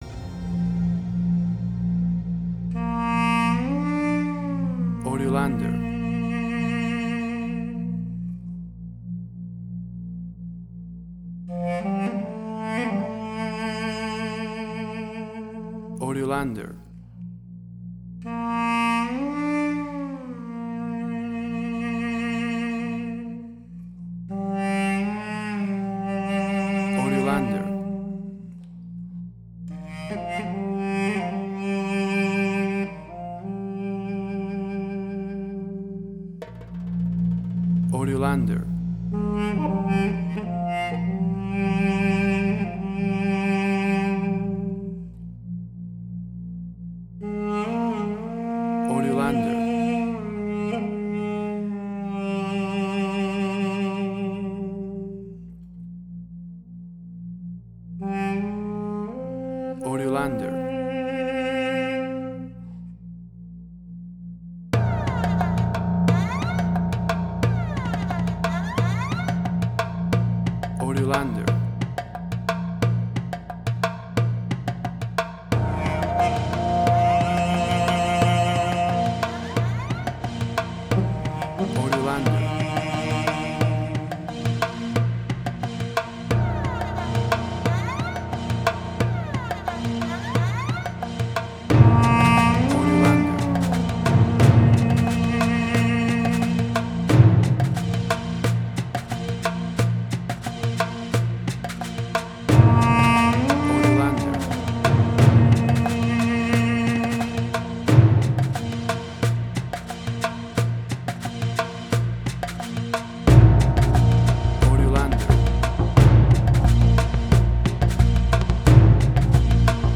Middle Eastern Fusion.
Tempo (BPM): 89